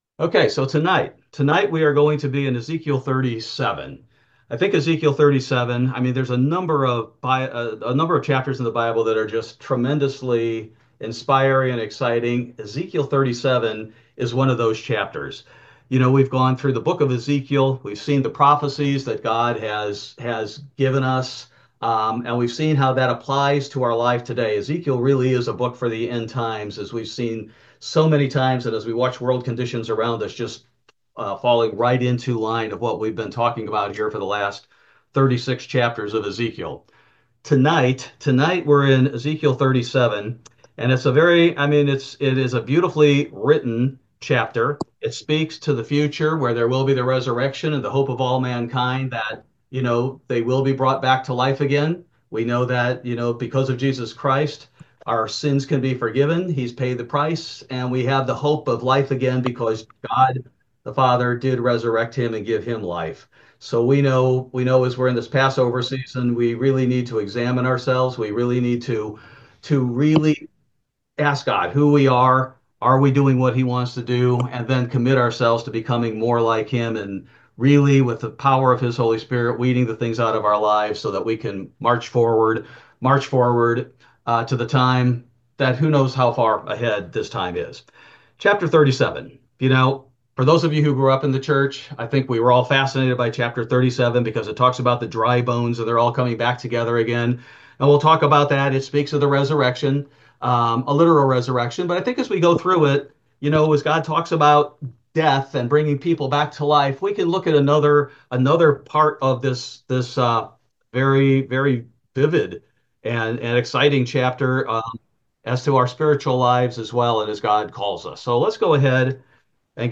Ezekiel Bible Study: March 5, 2025